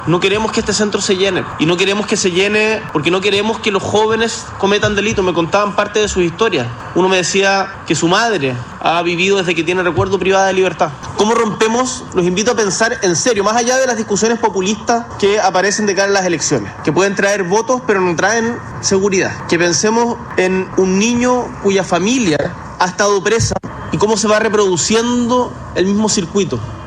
Por su parte, el Presidente de la República, Gabriel Boric, en su alocución, abordó sus conclusiones tras reunirse con 4 adolescentes privados de libertad al interior del recinto, que en la actualidad tiene 12 usuarios y capacidad para 72.